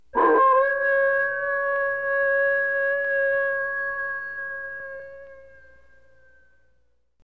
SFX夜晚狼对着月亮嚎叫的声音音效下载
SFX音效